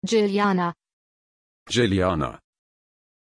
Aussprache von Jiliana
pronunciation-jiliana-de.mp3